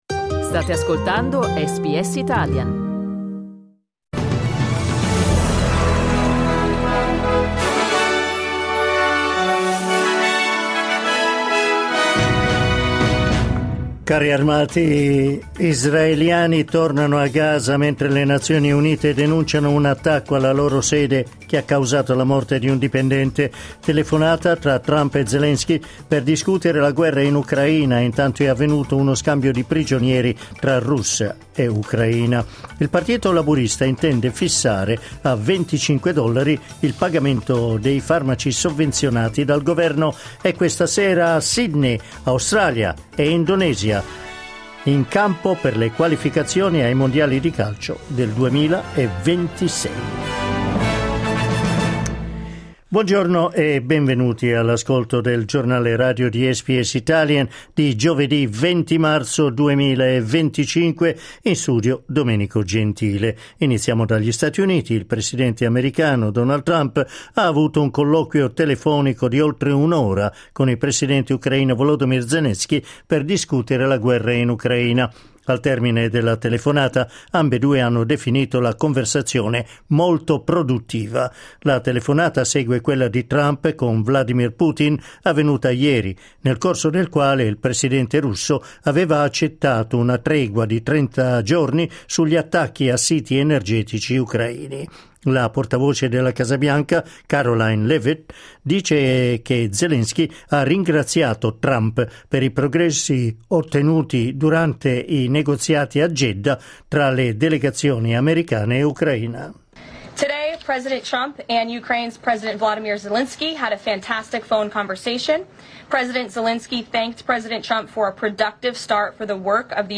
Giornale radio giovedì 20 marzo 2025
Il notiziario di SBS in italiano.